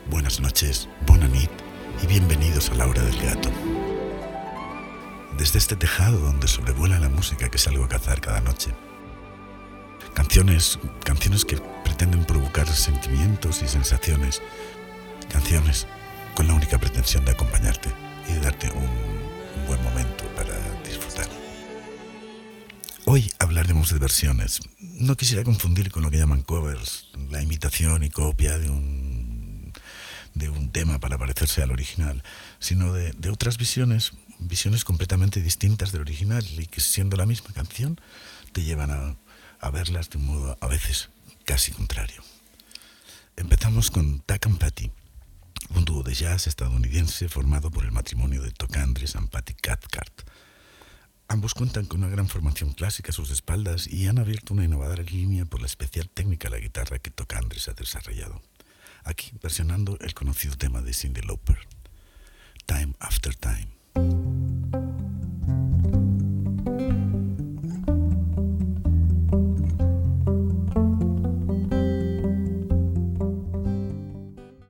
Presentació i tema musical
Musical